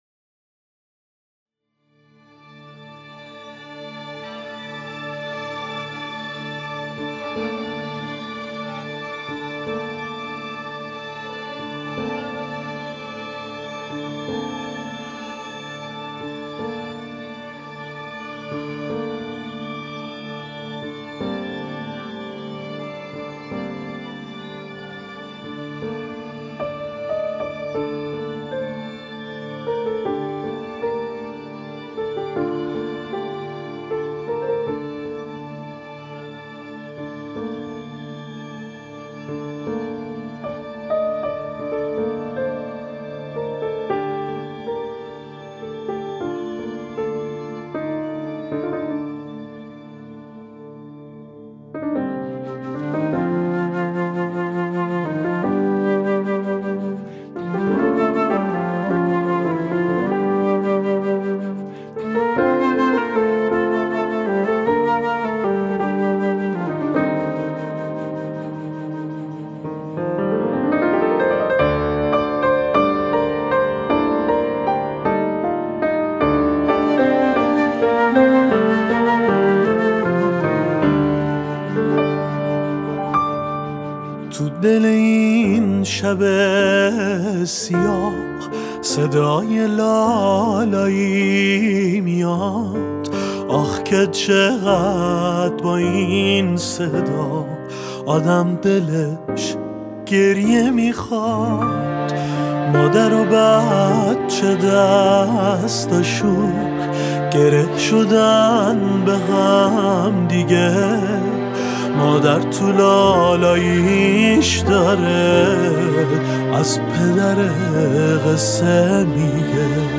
خواننده موسیقی پاپ